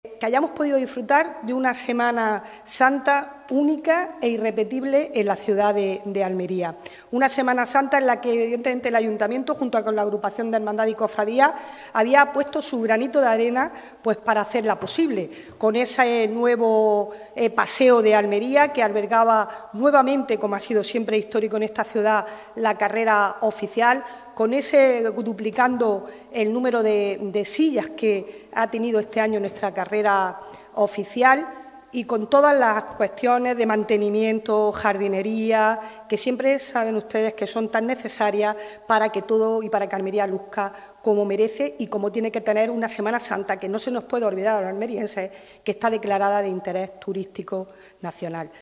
ELOISA-CABRERA-CONCEJAL-FIESTAS-MAYORES-BALANCE-SEMANA-SANTA.mp3